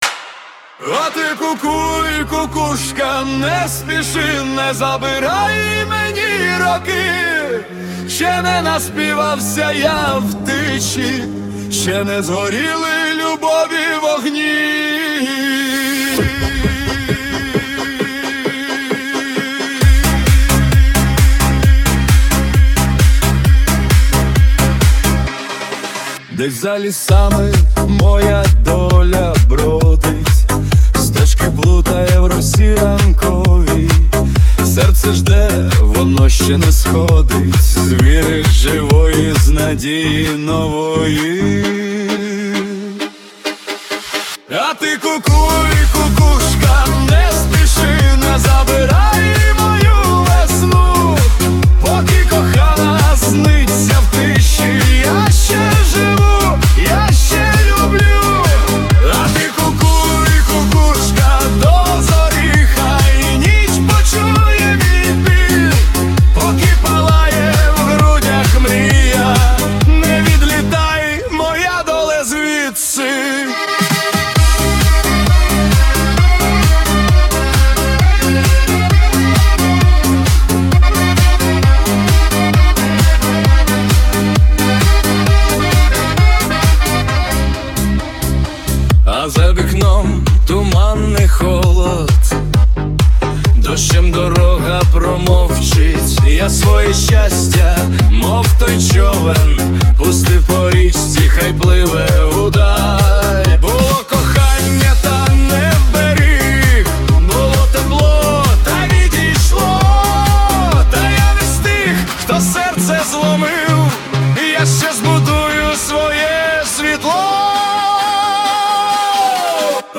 Чому така пісня сумна?